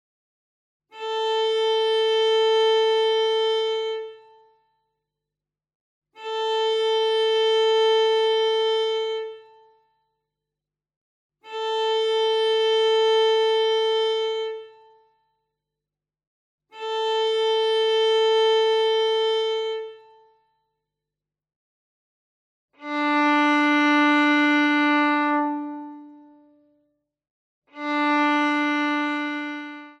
Besetzung: Violine
01 - Stimmtoene